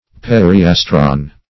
Search Result for " periastron" : The Collaborative International Dictionary of English v.0.48: Periastron \Per`i*as"tron\, n. [NL., fr. Gr. peri` about + 'a`stron a star.]